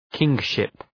Προφορά
{‘kıŋʃıp}